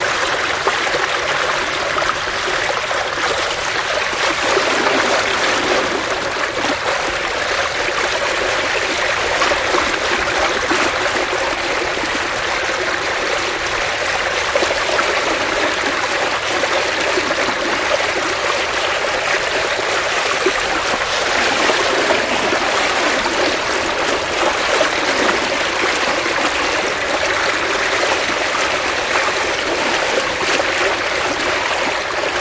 Lake Water Movement, Very Active